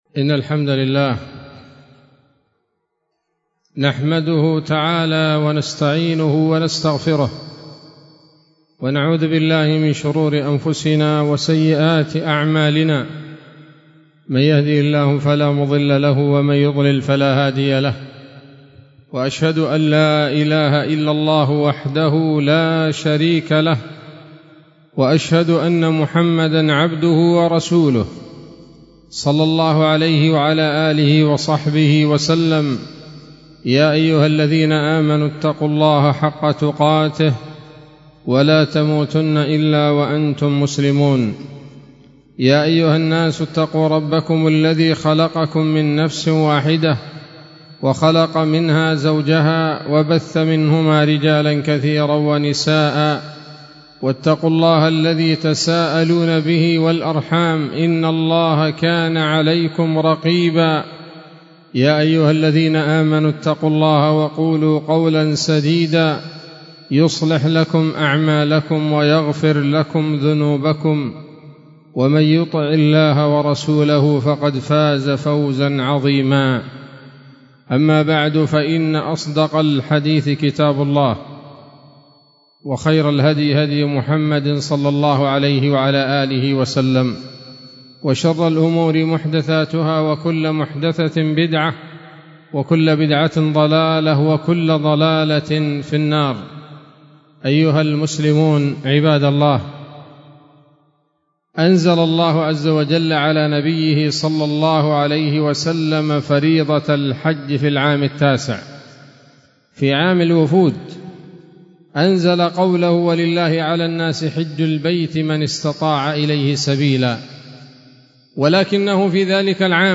خطبة جمعة بعنوان: (( السيرة النبوية [32] )) 26 صفر 1446 هـ، دار الحديث السلفية بصلاح الدين